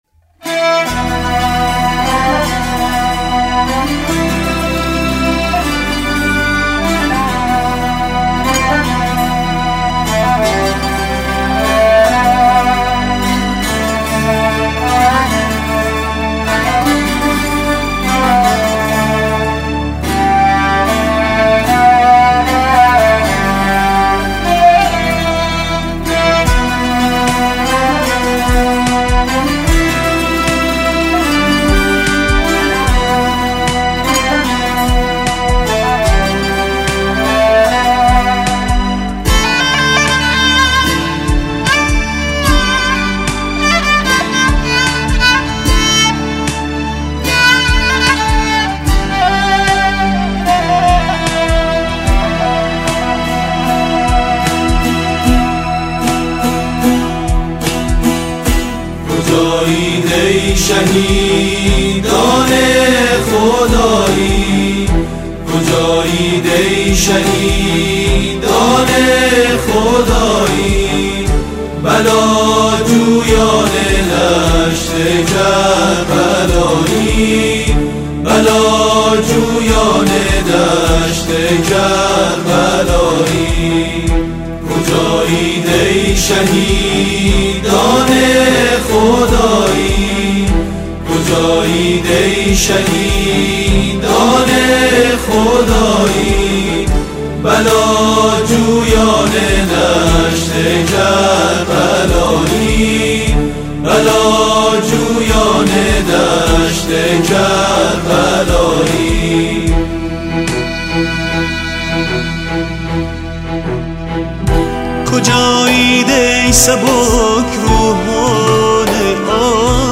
کجایید ای سبکبالان عاشق- پرنده تر ز مرغان هوایی/گروه سرود